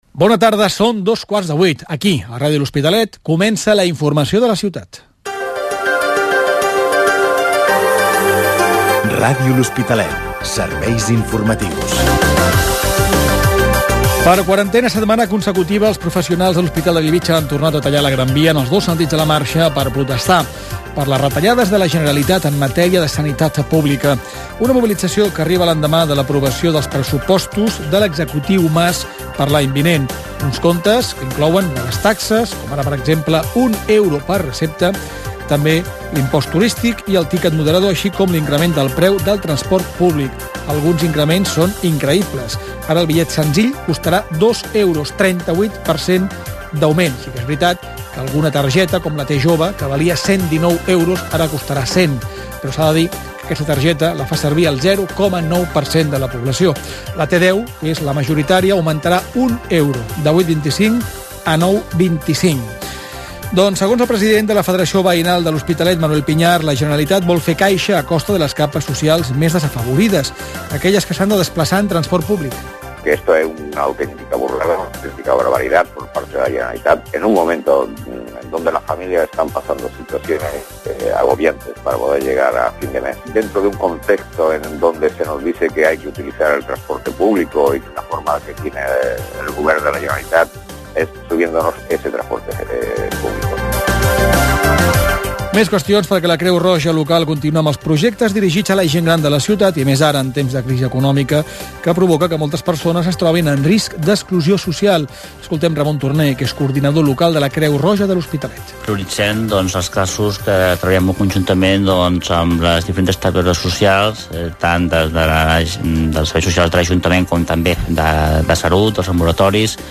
Hora, careta del programa, sumari, protestes del personal de l'Hospital de Bellvitge per les retallades a la sanitat pública fetes pel govern de la Generalitat, ampliació de l'Hospital de Bellvitge, increment dels preus del transport
Gènere radiofònic Informatiu